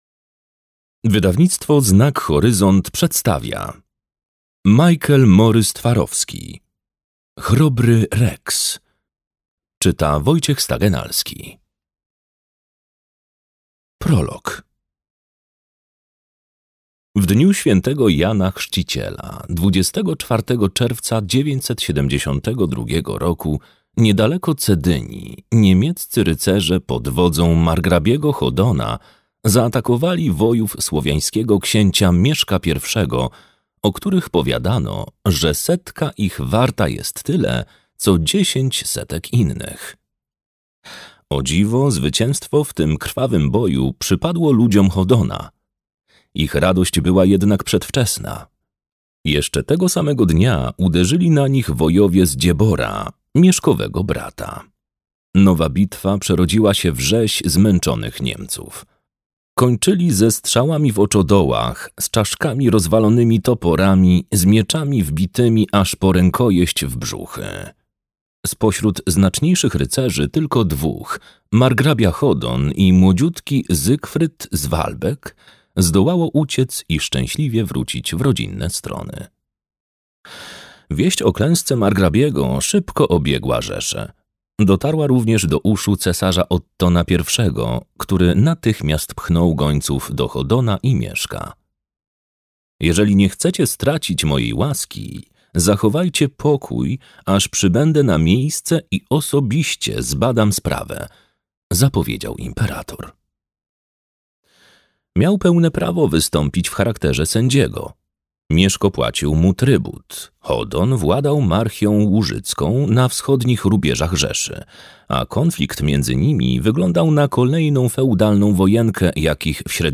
Chrobry Rex - Morys-Twarowski Michael - audiobook